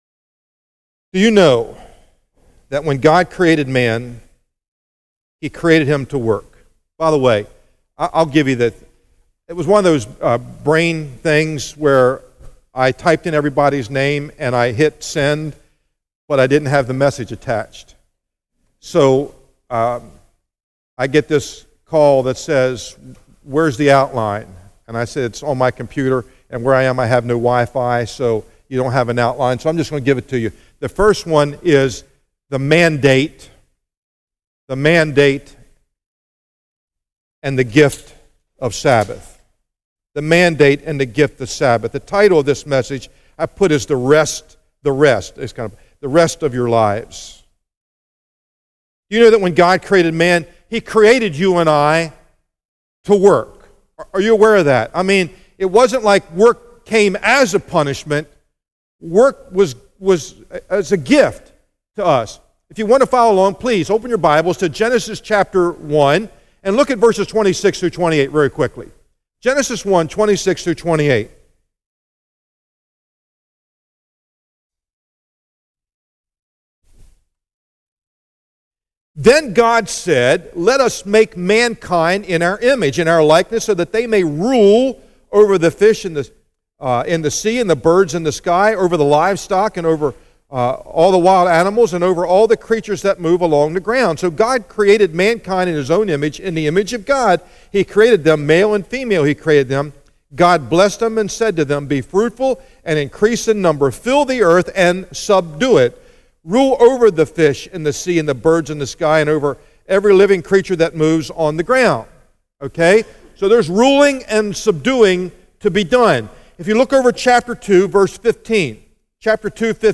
sermon text: Matthew 12:1-14